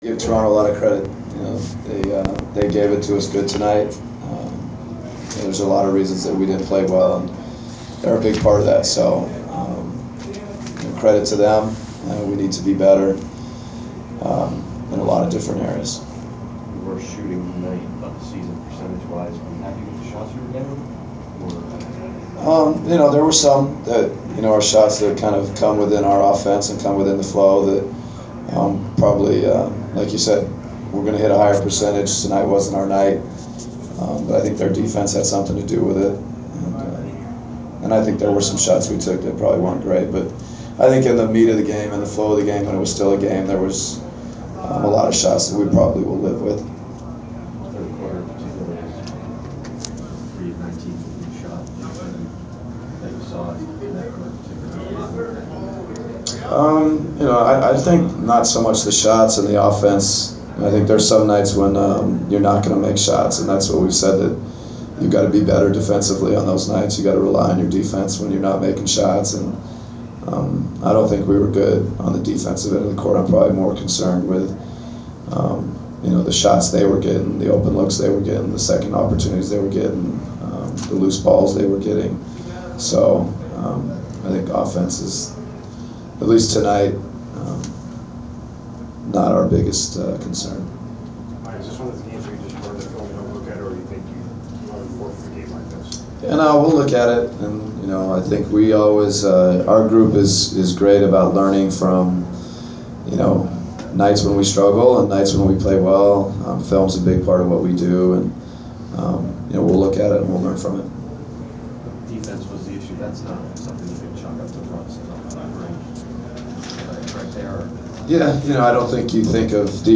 Inside the Inquirer: Postgame presser with Atlanta Hawks’ head coach Mike Budenholzer (2/20/15)
We attended the postgame presser of Atlanta Hawks’ head coach Mike Budenholzer following his team’s 105-80 home defeat to the Toronto Raptors on Feb. 20. Topics included the Hawks’ struggles on the defensive end and lack of overall effort.